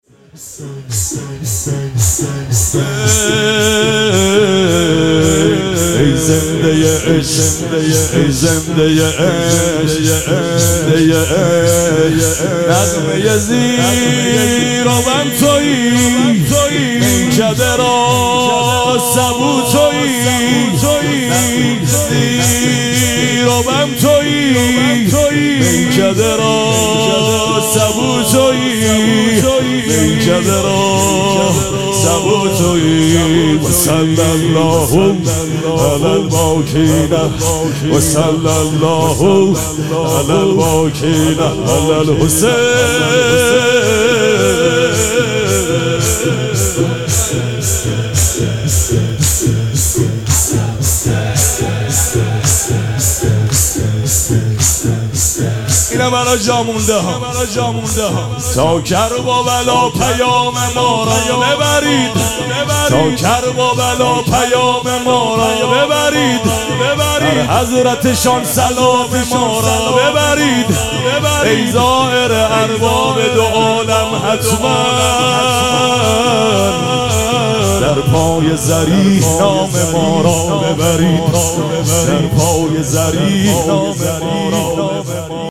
شب سوم مراسم عزاداری اربعین حسینی ۱۴۴۷
مراسم عزاداری اربعین حسینی